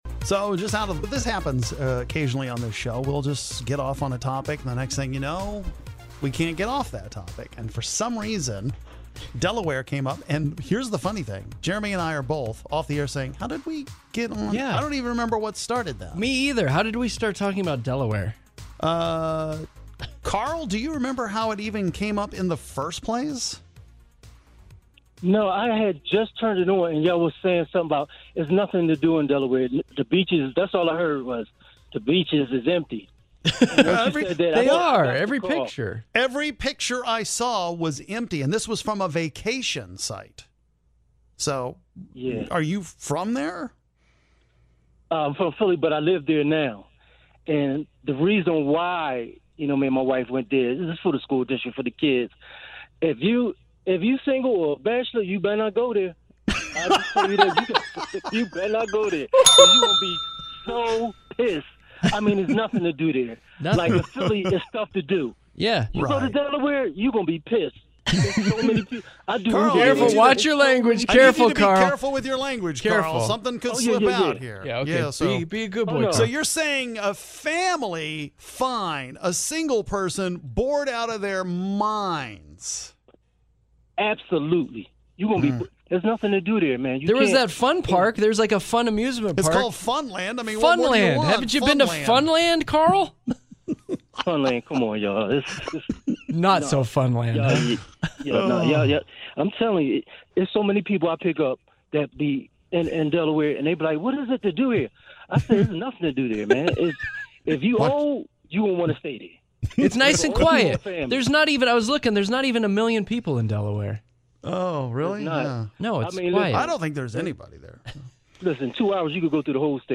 We get some calls after we cannot get Delaware out of our minds.